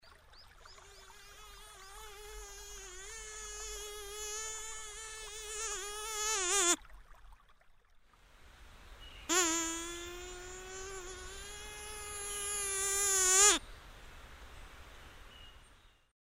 На этой странице собраны звуки писка комаров – от раздражающего высокочастотного звона до приглушенного жужжания.
Комар опустился на землю